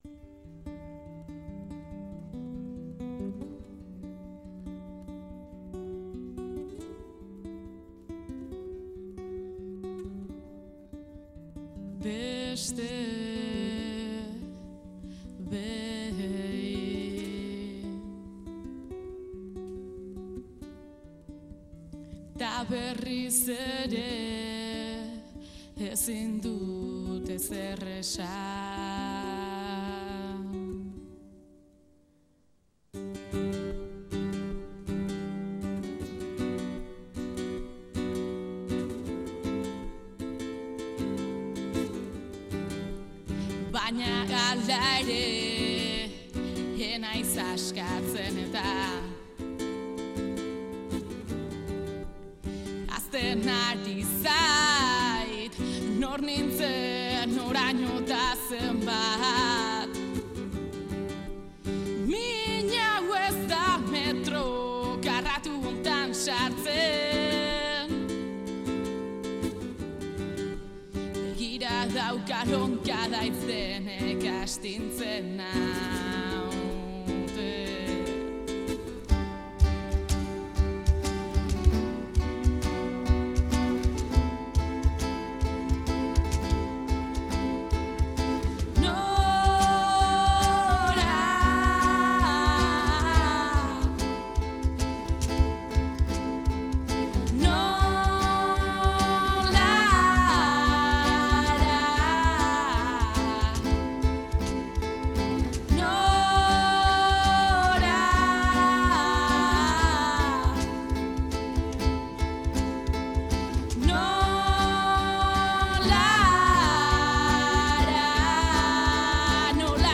hirukote zarauztarrak
gitarra akustikoan
perkusioan